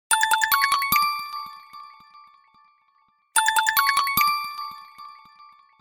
• Качество: 129, Stereo
Стандартный рингтон